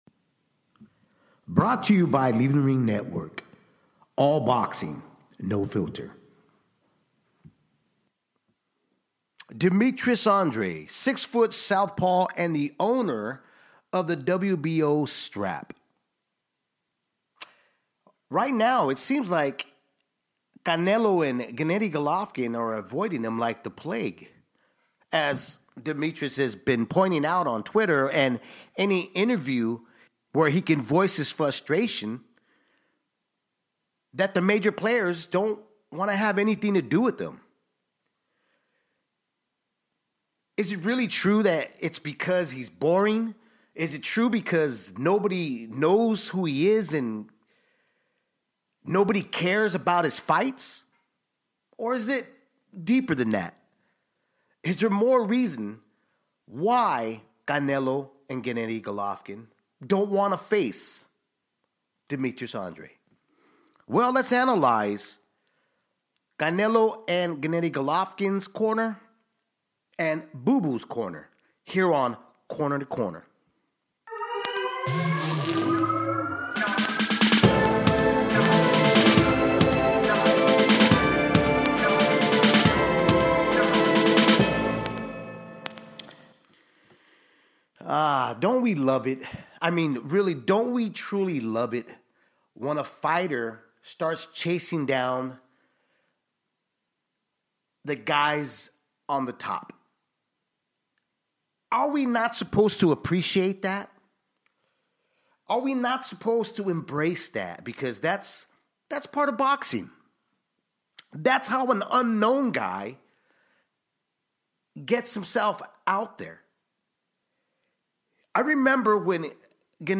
Corner to Corner is a solo podcast show